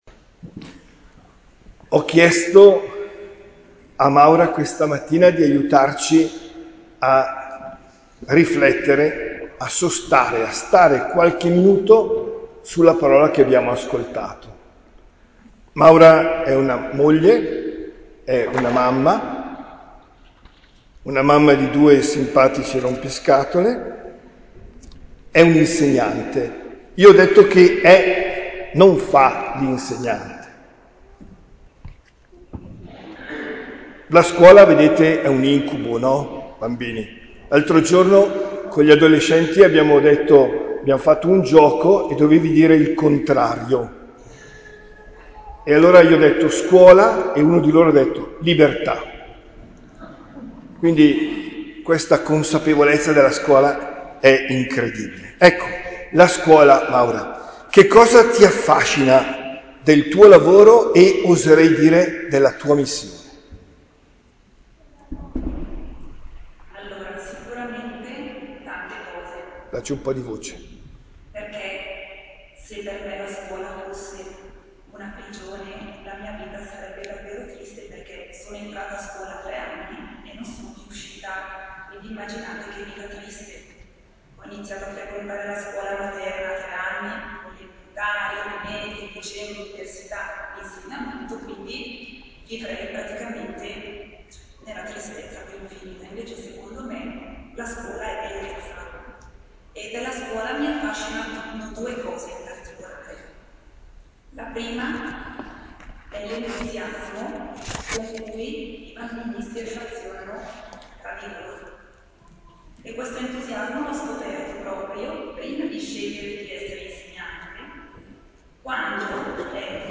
OMELIA DEL 11 DICEMBRE 2022